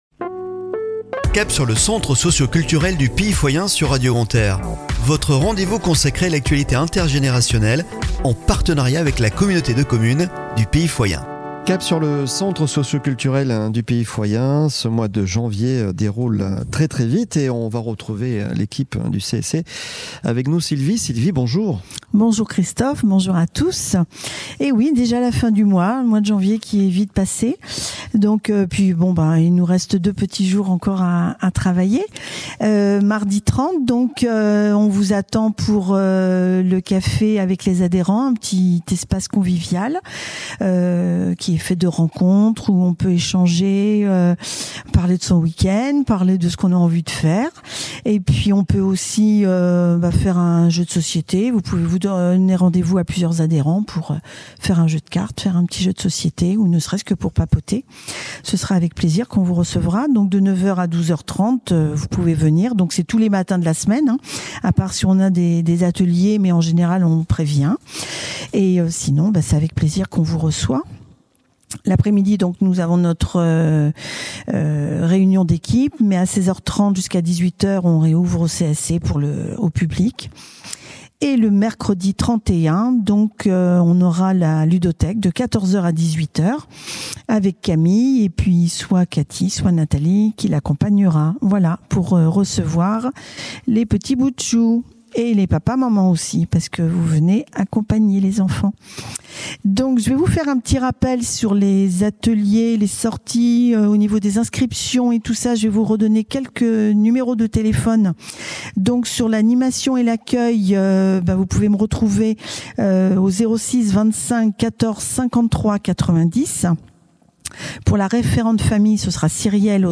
avec la parole aux adhérents